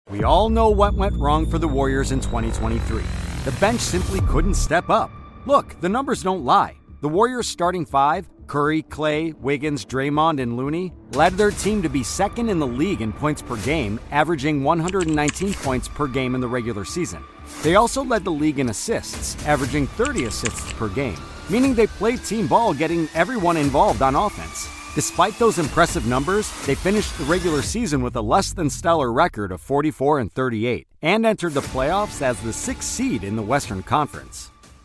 Male
Warm, intelligent American male voice for brands that value trust, clarity, and real human connection. I bring an actor's instinct, musicality, and restraint to every read—delivering confident, natural performances that feel grounded, believable, and never synthetic.
Narration